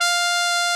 Index of /90_sSampleCDs/Roland L-CD702/VOL-2/BRS_Tpt Cheese/BRS_Cheese Tpt
BRS TRUMPE0L.wav